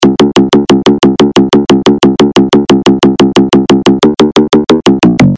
描述：电子、SFX、Dit、怪异、8Beat
标签： 120 bpm Electronic Loops Fx Loops 918.87 KB wav Key : Unknown
声道立体声